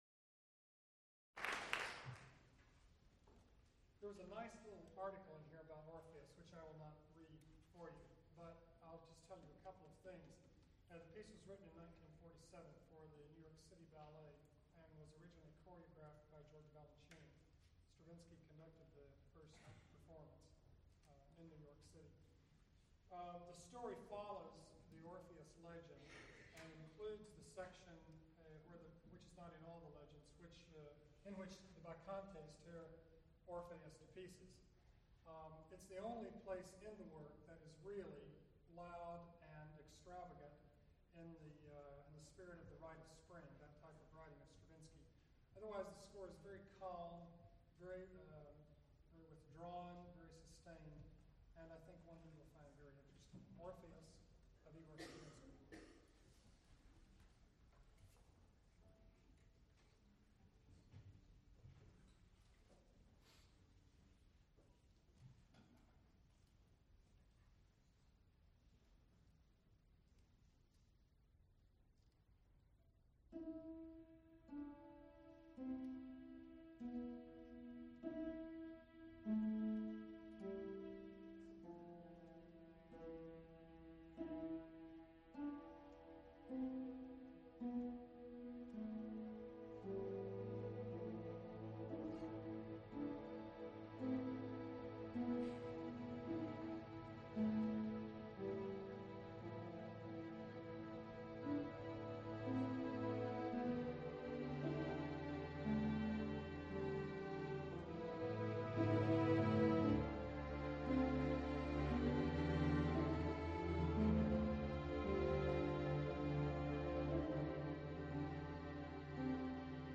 Recorded live November 24, 1978, Schenley Hall, University of Pittsburgh.
Extent 2 audiotape reels : analog, quarter track, 7 1/2 ips ; 12 in.
Genre musical performances